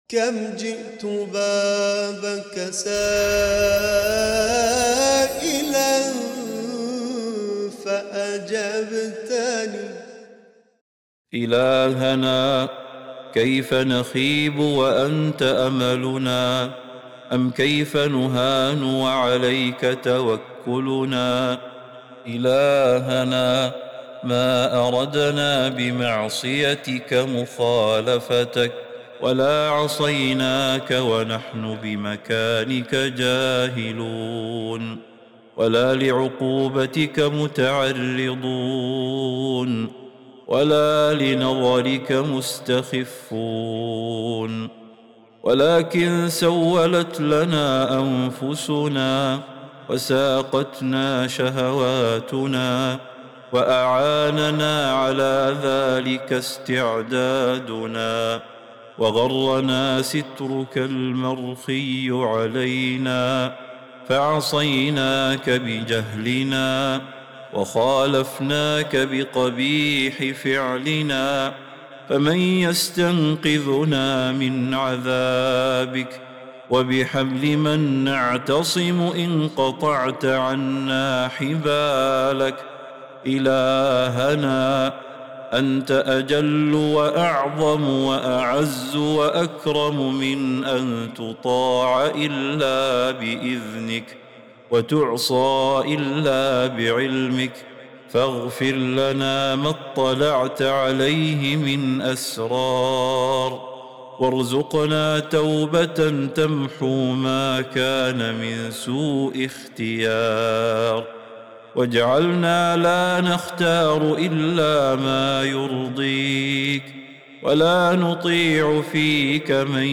دعاء خاشع يعترف فيه العبد بذنبه وتقصيره أمام ربه، مع التوسل بعفوه ورحمته. يعبر النص عن شعور عميق بالندم والرجاء، ويسأل الله الثبات على الطاعة والمغفرة لما سلف من الذنوب.